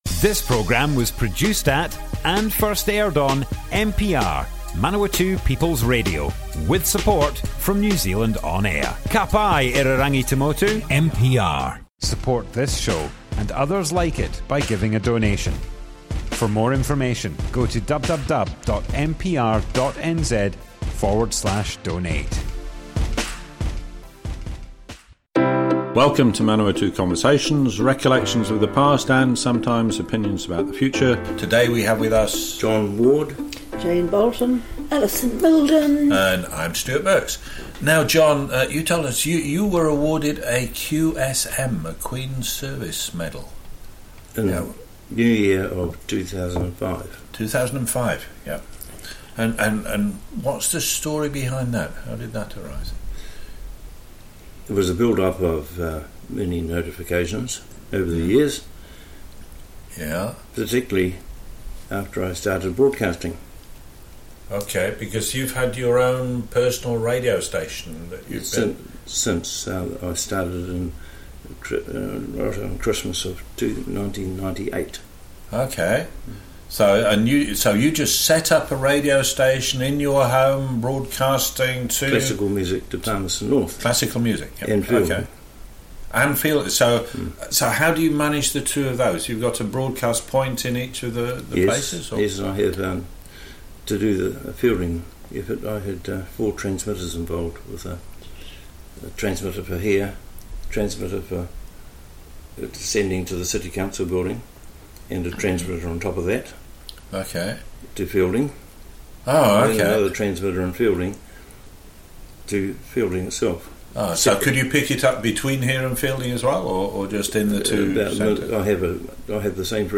Manawatu Conversations More Info → Description Broadcast on Manawatu People's Radio, 15th September 2020.
oral history